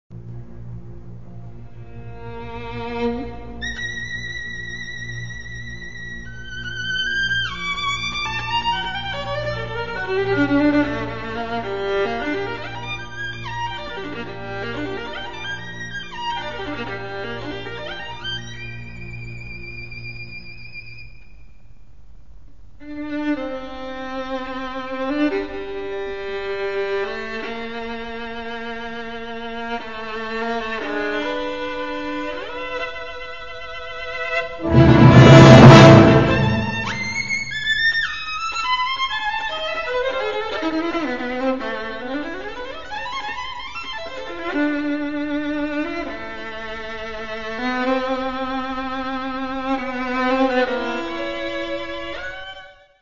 Un sens inné du phrasé, une technique à faire pâlir les virtuoses eux-même.
Tout la panoplie des coups d'archets est présente dans ce concerto, ricochets, staccato, martelés etc...
le tempo, pas trop rapide donne à l'œuvre un caractère encore plus profond s'il en était besoin.
impressionnante de douceur dans les pianissimi
Pièces pour violon et orchestre